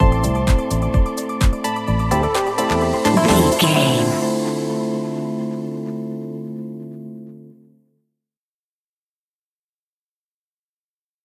Aeolian/Minor
groovy
uplifting
energetic
drums
synthesiser
electric piano
bass guitar
funky house
deep house
nu disco